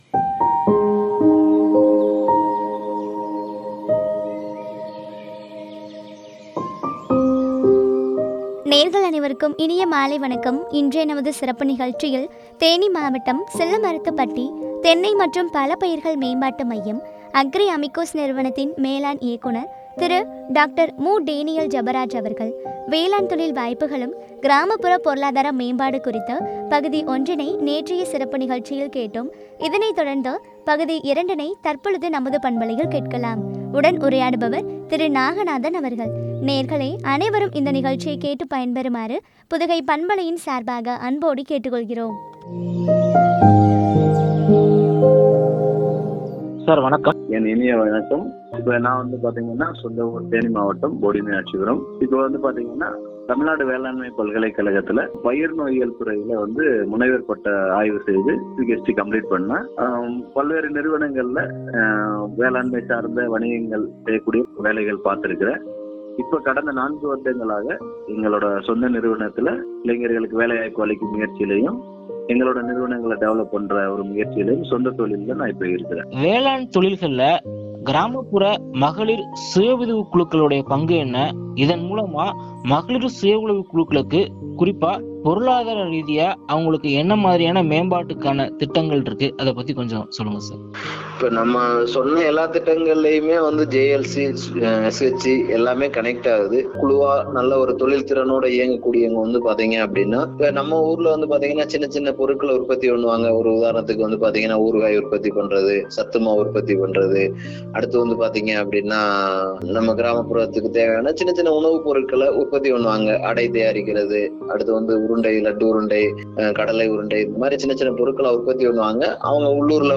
பற்றிய உரையாடல்.